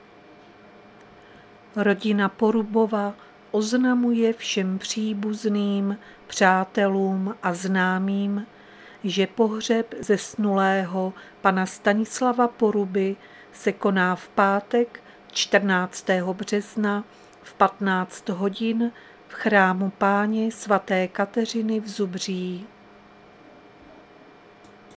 Záznam hlášení místního rozhlasu 13.3.2025
Zařazení: Rozhlas